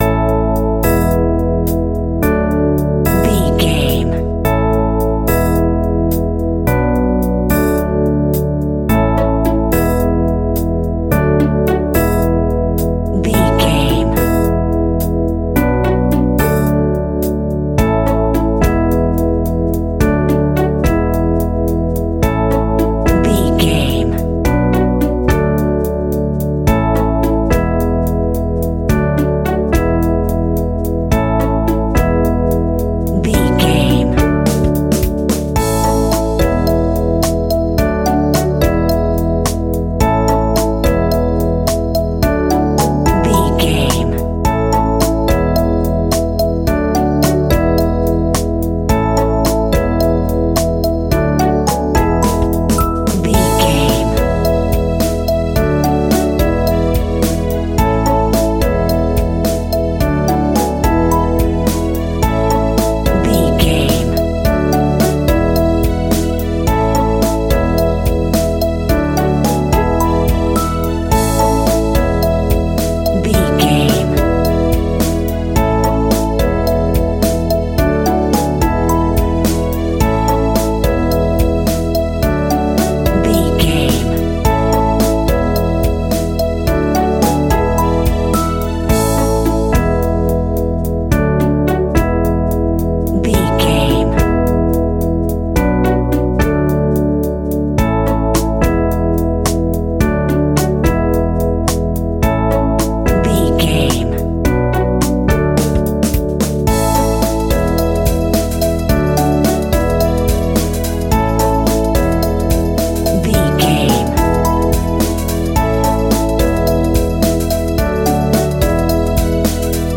Uplifting
Ionian/Major
kids music
childlike
happy
kids piano